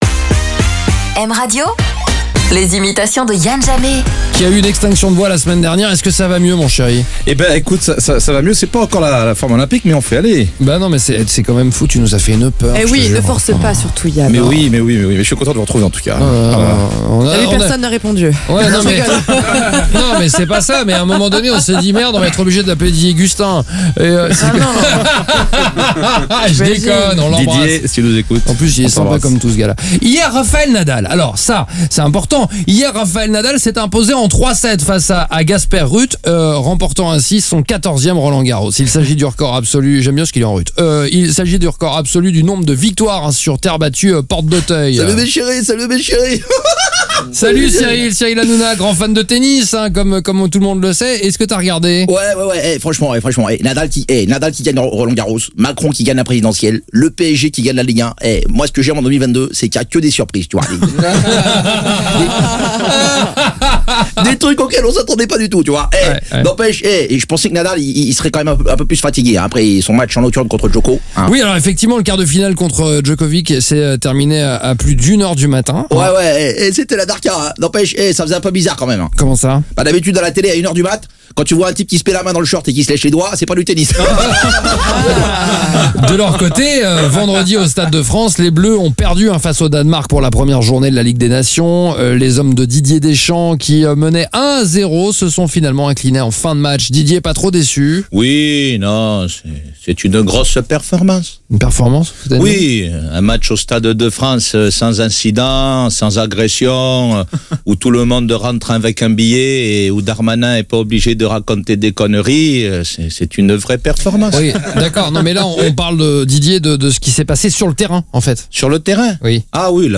refaire l’actualité avec ses imitations